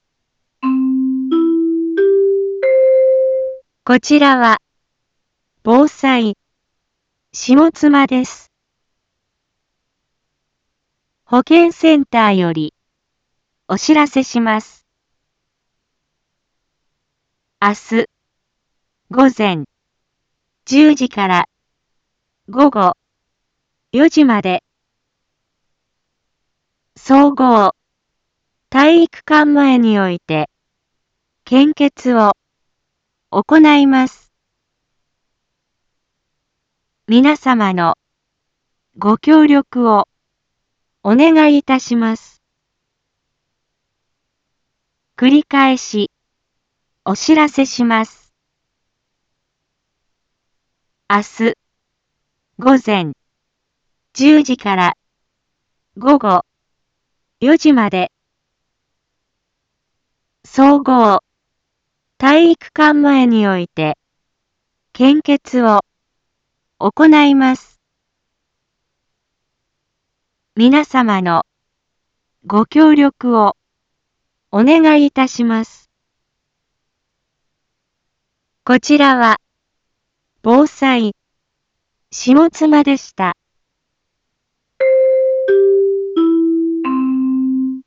一般放送情報
Back Home 一般放送情報 音声放送 再生 一般放送情報 登録日時：2022-12-21 18:31:28 タイトル：【前日報】献血のお知らせ インフォメーション：こちらは、防災、下妻です。